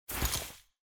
UI_ResourceChange.mp3